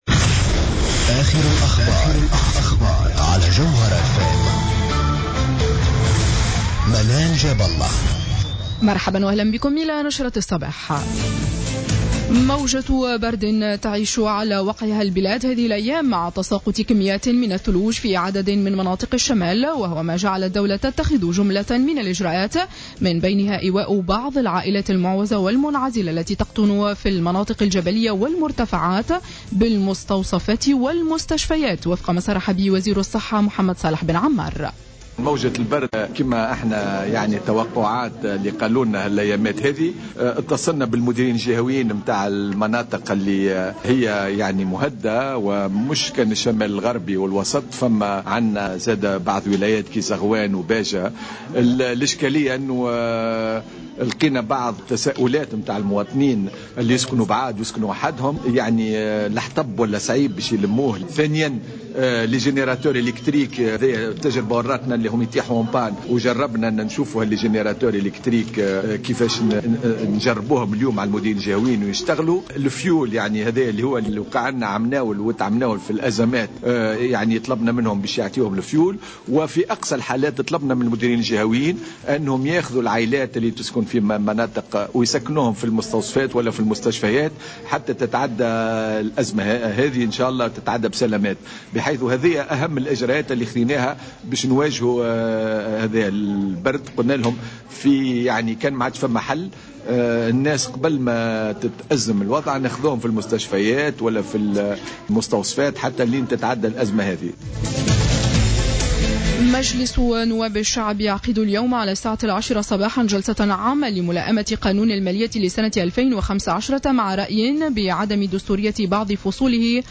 نشرة اخبار السابعة صباحا ليوم الثلاثاء 30 ديسمبر 2014